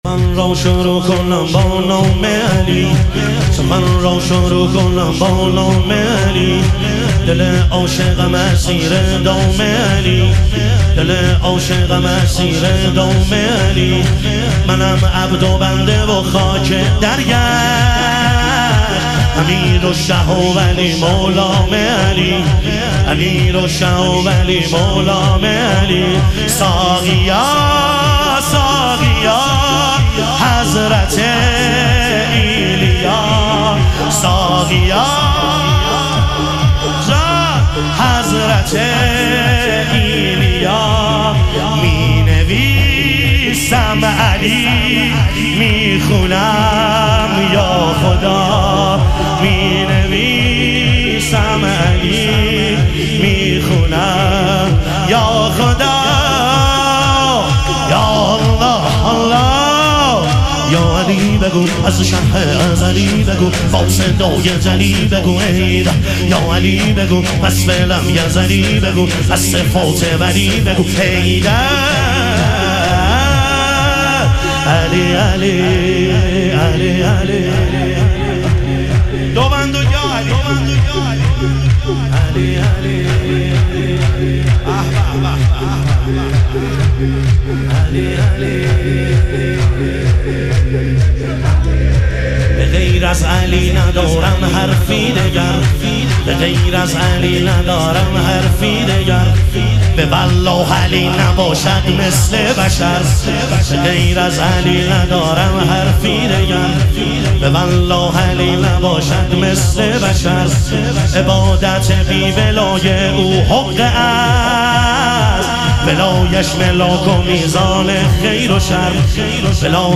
شور
شب اربعین امیرالمومنین علیه السلام